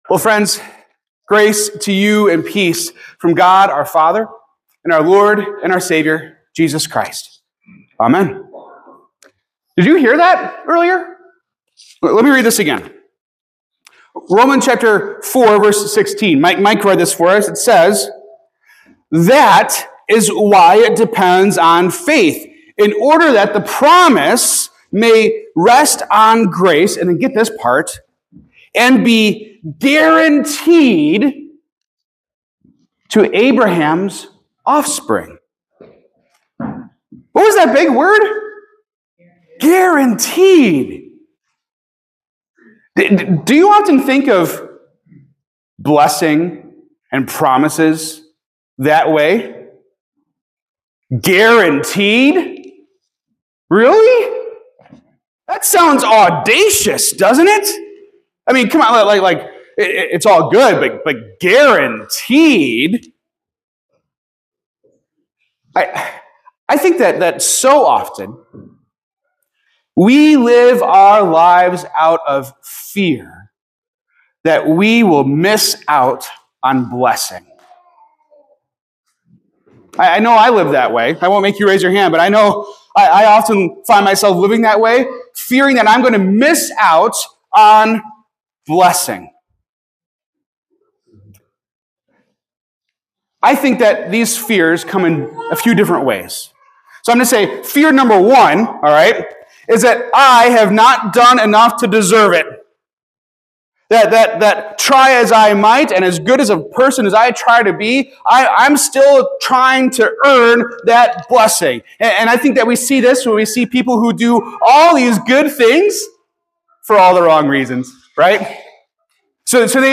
In a world that constantly tempts us to secure blessing on our own terms — through performance, despite our failures, or in spite of our circumstances — God interrupts with an audacious promise: blessing that is guaranteed. This sermon traces how God called an undeserving idolater named Abraham and made him a promise that he would be a blessing for all nations, delivered through Abraham's offspring, Jesus.